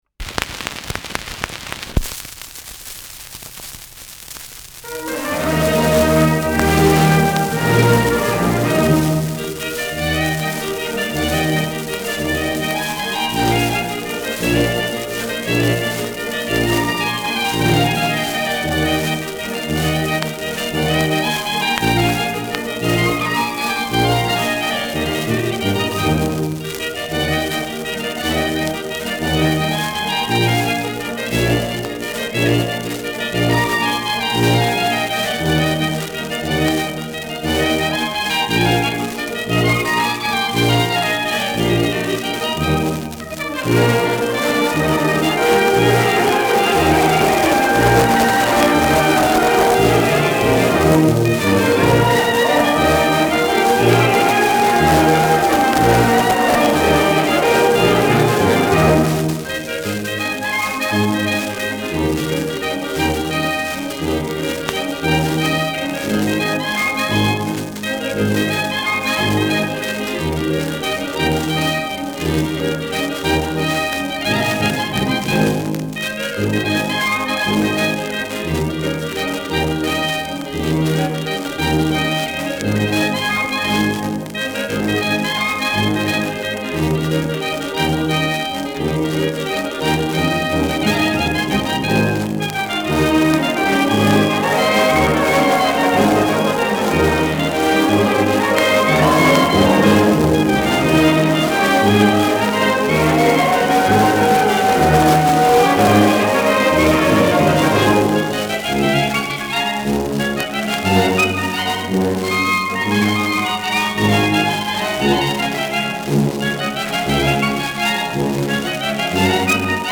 Schellackplatte
Auffällig langsam : Stark abgespielt : Nadelgeräusch
Große Besetzung mit viel Hall, die einen „symphonischen Klang“ erzeugt. Mit rhythmischem Klatschen.
[Berlin] (Aufnahmeort)